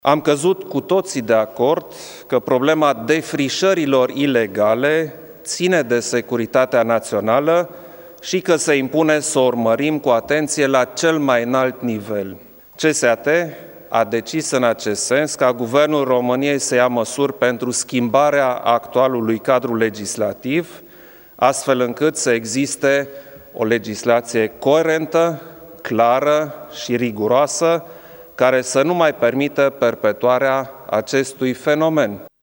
Preşedintele Klaus Iohannis a declarat, la finalul ședinței, că s-a decis luarea de către Guvern a unor măsuri pentru schimbarea cadrului legislativ.